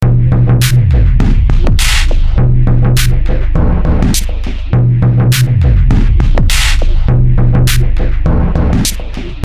节拍器点击和铃声 " metronom klack
描述：机械节拍器点击。用内置麦克风的Edirol R09录音。
标签： 点击 节拍器
声道立体声